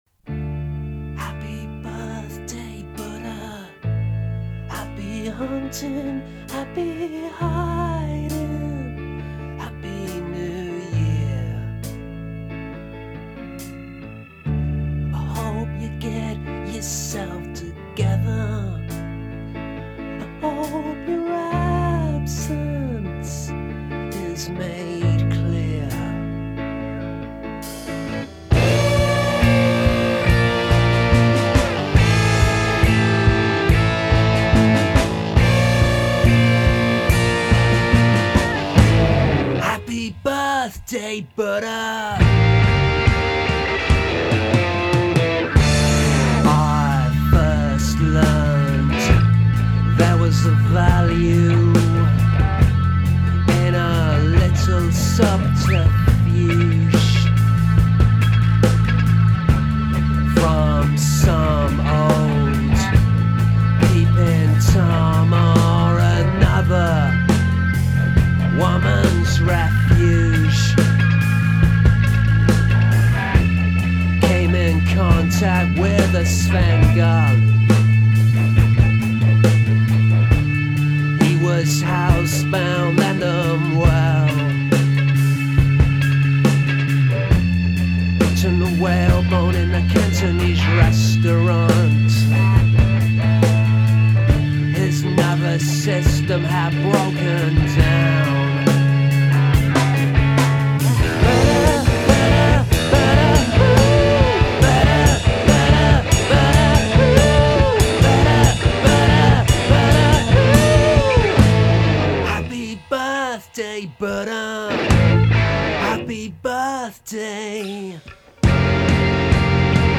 singer-songwriter/guitar/vocals and piano